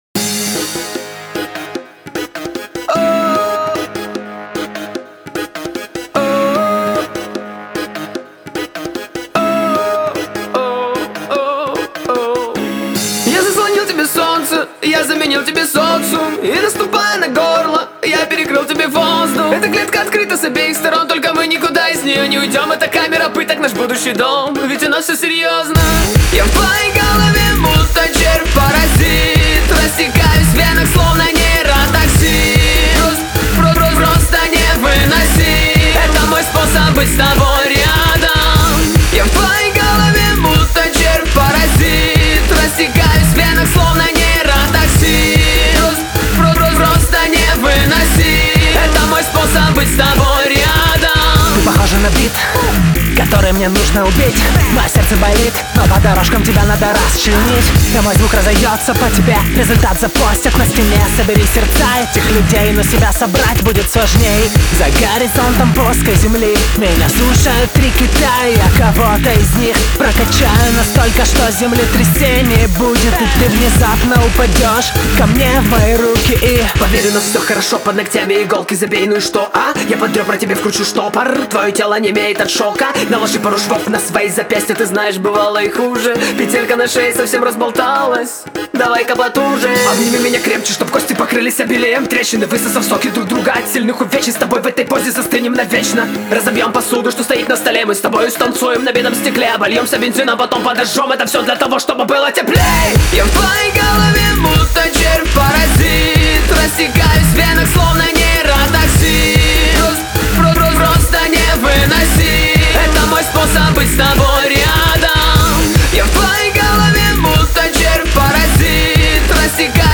Зарубежный рэп